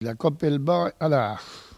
Patois
Locution